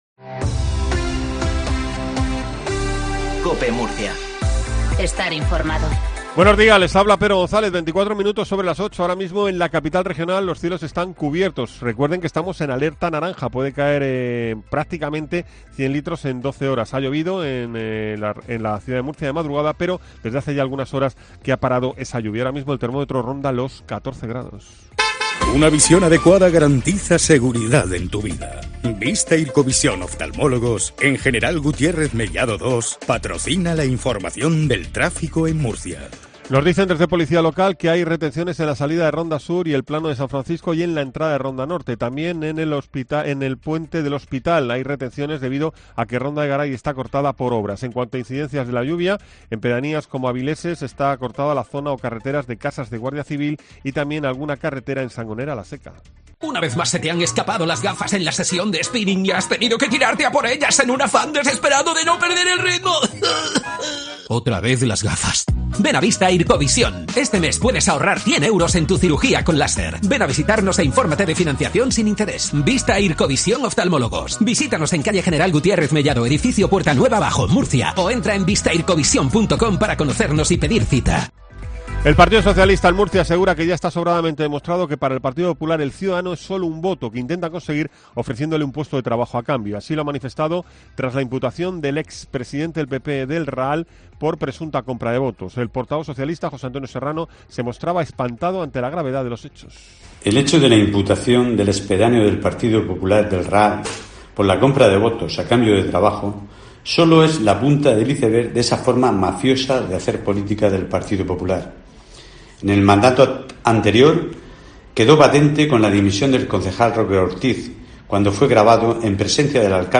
INFORMATIVO MATINAL COPE EN MURCIA 08.20